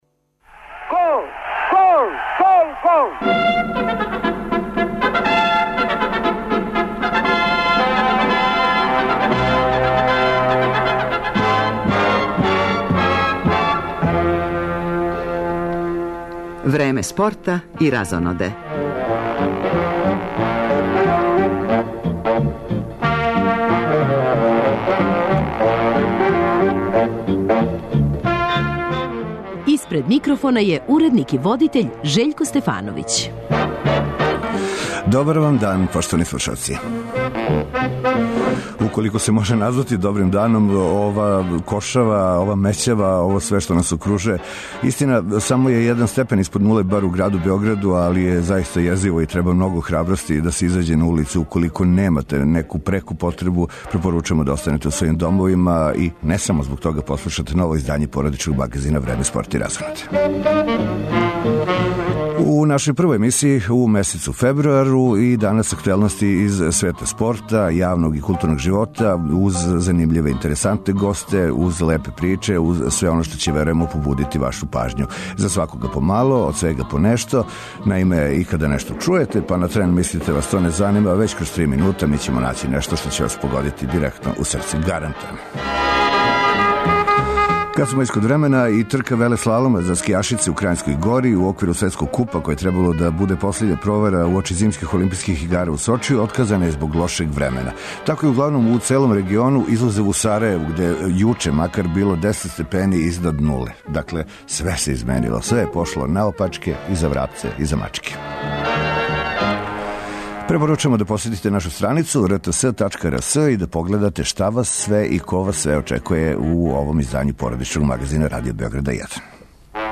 На почетку првог фебруарског издања породичног магазина Радио Београда 1 пратимо кретање резултата у мечу дублова, у оквиру Дејвис куп такмичења, у сусрету између Србије и Швајцарске.